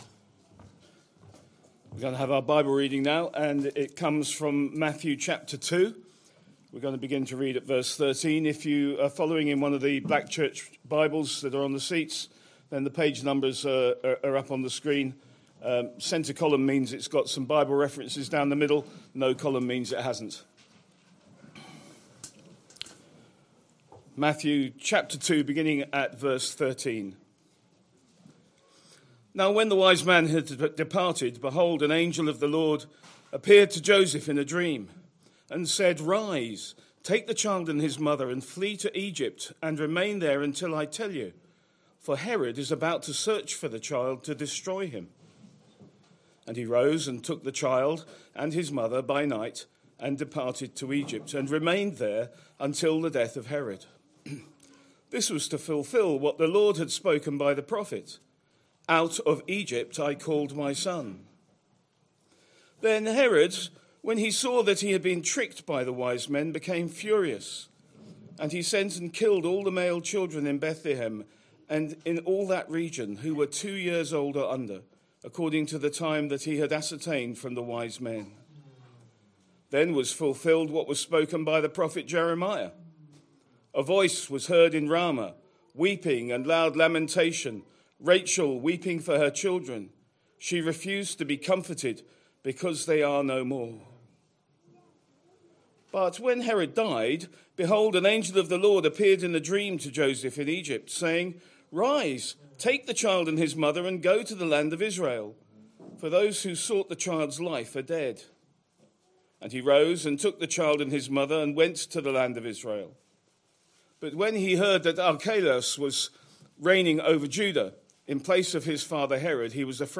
Sunday AM Service Sunday 28th December 2025 Speaker